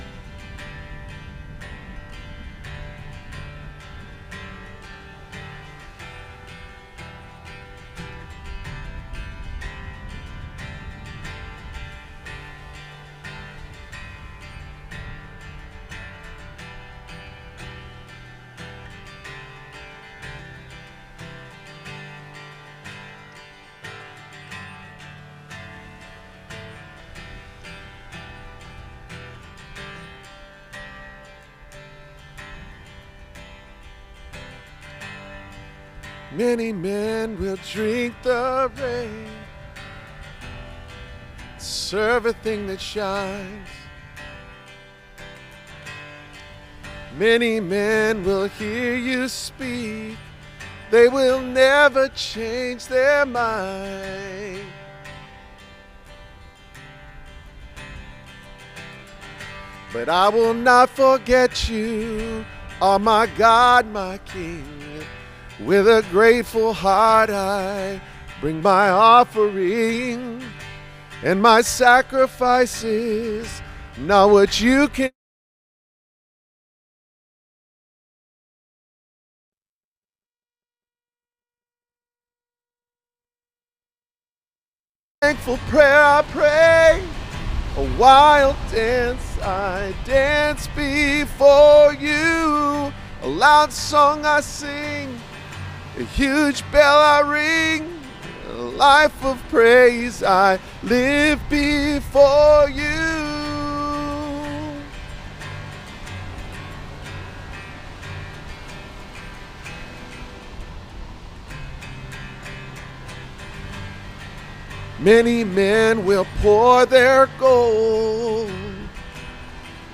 This sermon explores Jacob’s encounter with God’s angels and his fearful meeting with Esau, revealing how divine grace precedes human fear. It teaches that while fear is real, faith responds through humble prayer, confession, and trust in God’s promises. Ultimately, believers are called to rest in God’s faithfulness, finding peace and reconciliation through Christ.